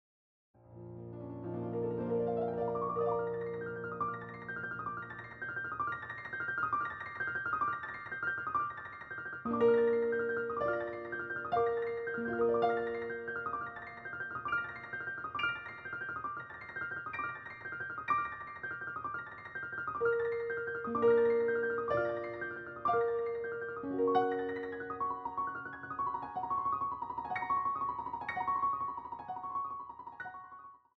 A quality audio recording of an original piano roll